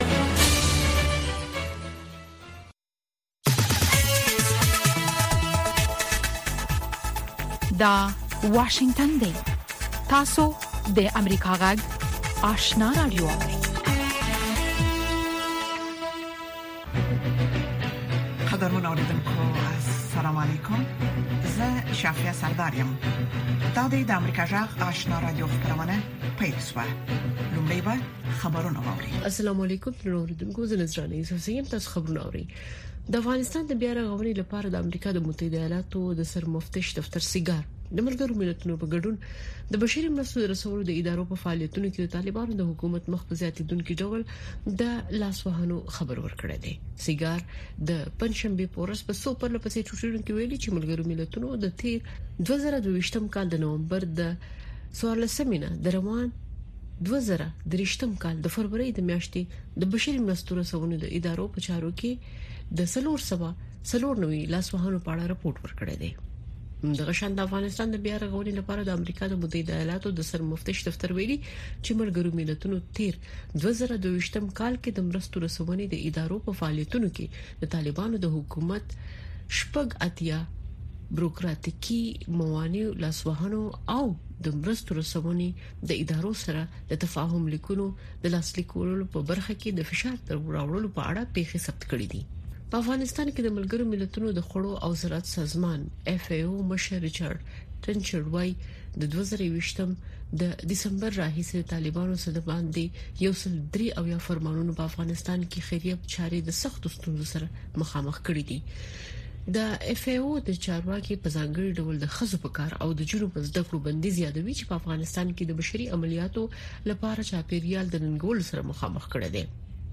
دویمه سهارنۍ خبري خپرونه
په سهارنۍ خپرونه کې د افغانستان او نړۍ تازه خبرونه، څیړنیز رپوټونه او د افغانستان او نړۍ د تودو پیښو په هکله مرکې تاسو ته وړاندې کیږي.